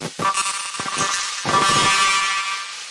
描述：用REAKTOR制作的古怪循环和垫子
标签： 125 bpm Weird Loops Fx Loops 1.75 MB wav Key : Unknown
声道立体声